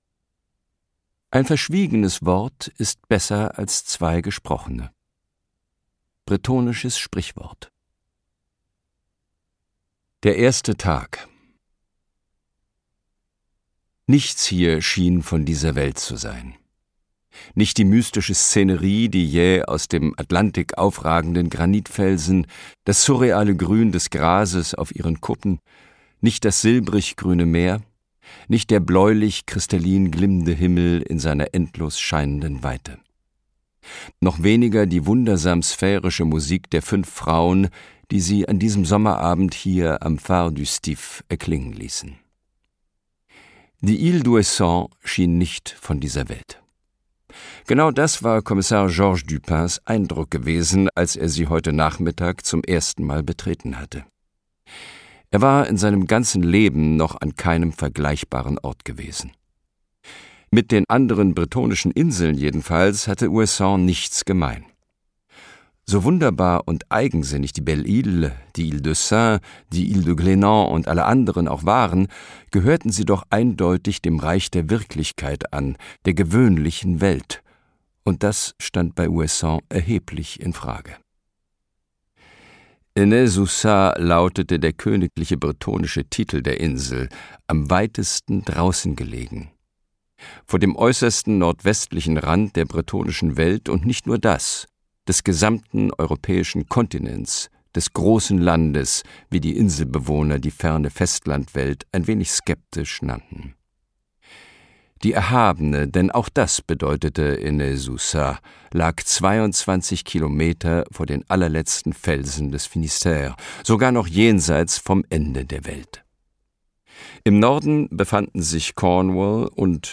Bretonische Sehnsucht Kommissar Dupins dreizehnter Fall Jean-Luc Bannalec (Autor) Christian Berkel (Sprecher) Audio Disc 2024 | 1.